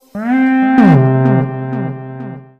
короткие
космические
электронные